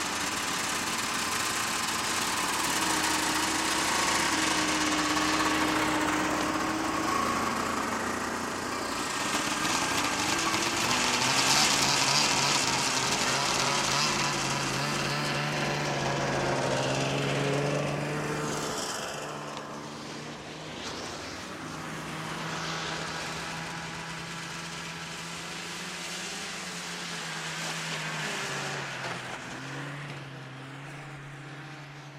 努纳维克 " 雪地摩托嘎嘎作响地开来开去，拉走慢速行驶
描述：雪地摩托车快速行驶，拉开慢速
Tag: 速度 雪地摩托 rattly 开车 前后左右缓慢